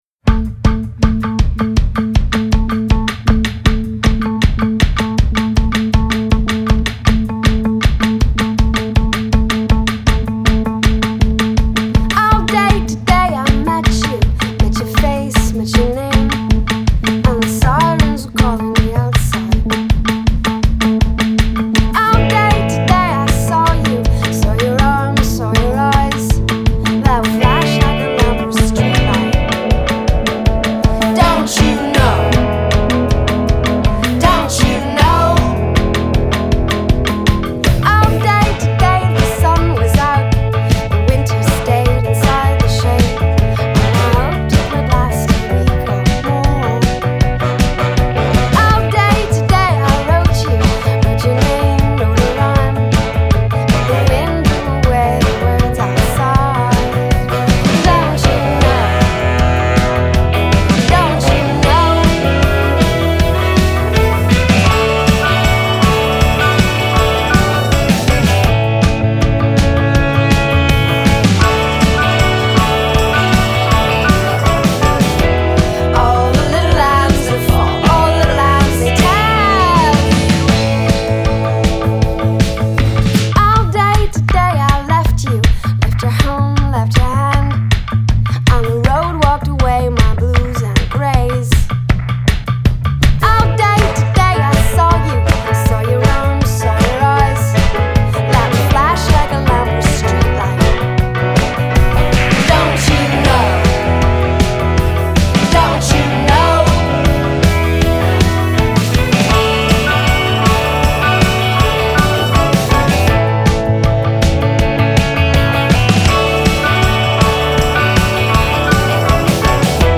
a half warble-half shout
starts with some upbeat syncopated percussion
the arrangement opens into a poppy, swaggering brassy affair
indie rock